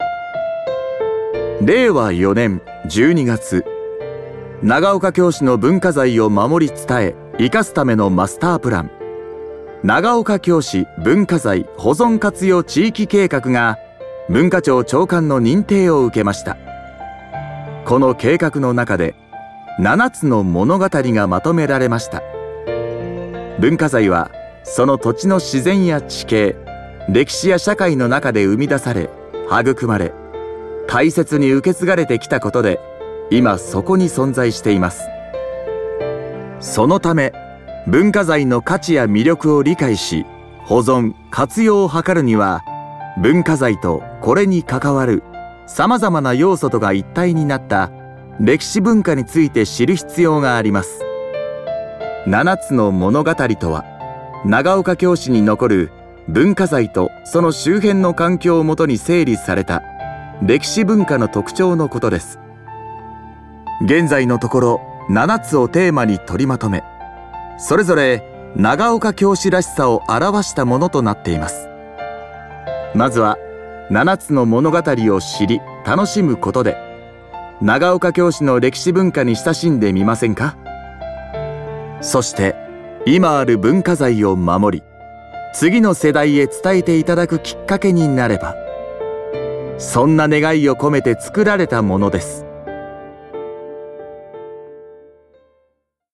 音声ガイド